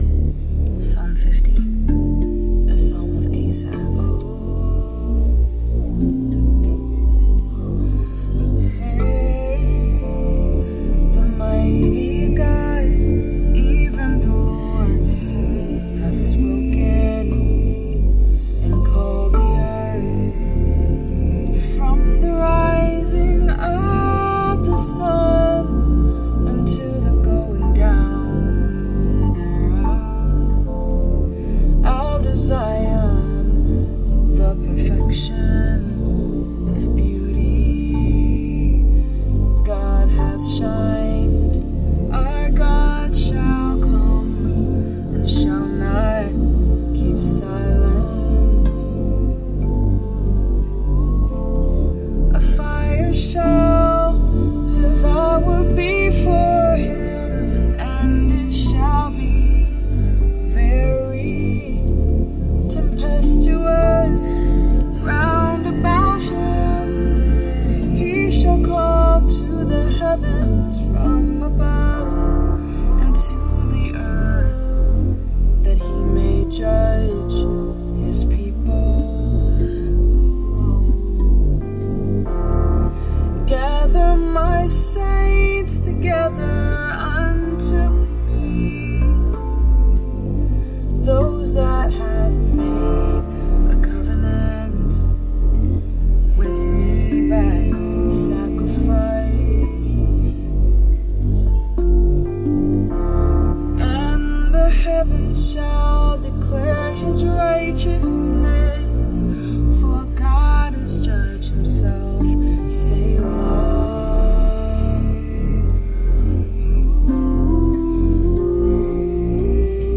The 2nd time I stayed up and went into the recording room. A beat I had previously made was sitting waiting for me to use it with my Rav Vast Drum. Then I decided to record the next psalm and it happened to be Psalm 50.